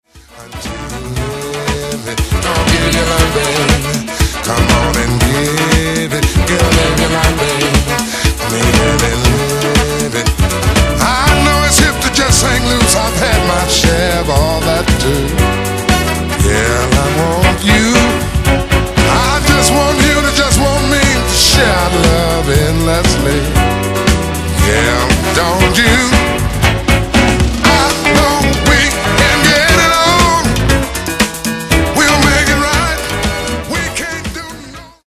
Genere:   Disco | Soul